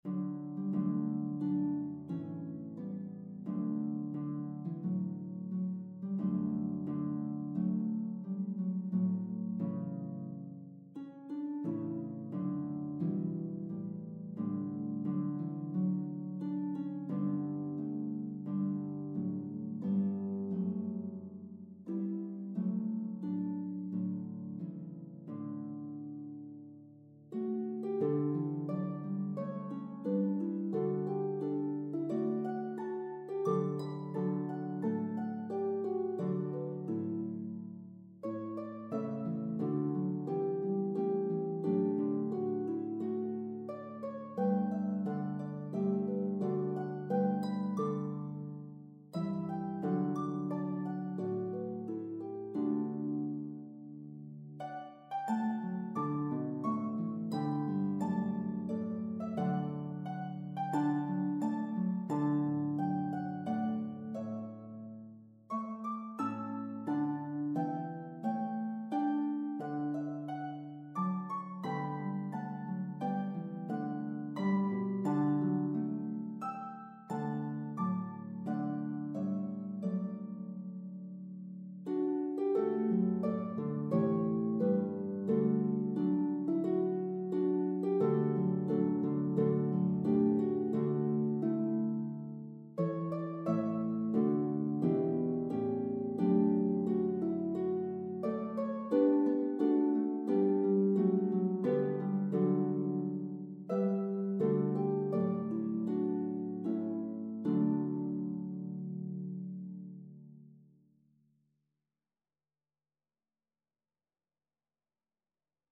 is for two lever or pedal harps
The melody is evenly divided between both harp parts.